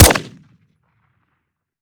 weap_mike9a3_fire_plr_01.ogg